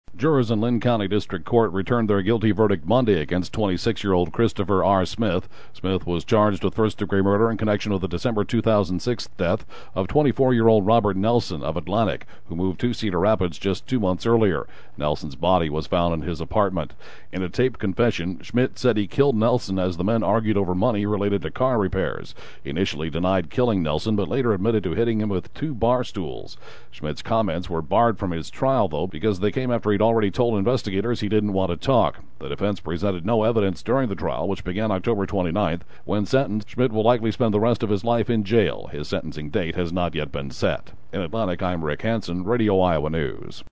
report.